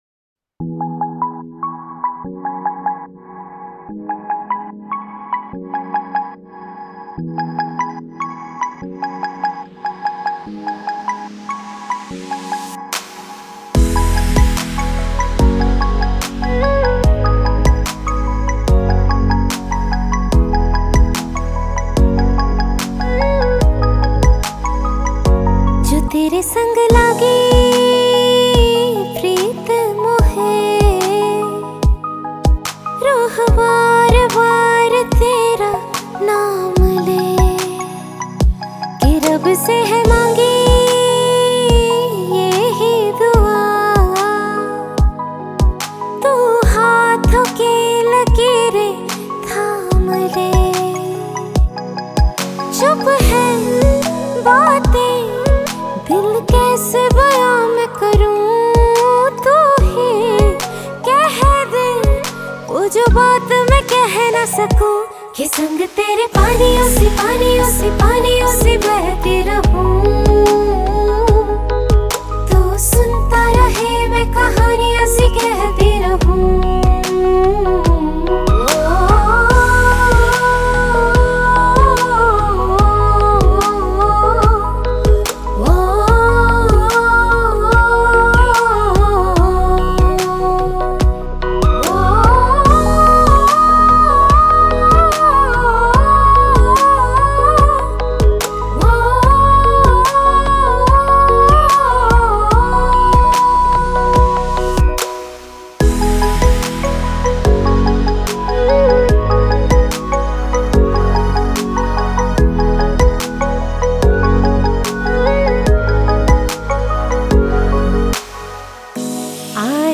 Bollywood Mp3 Music 2018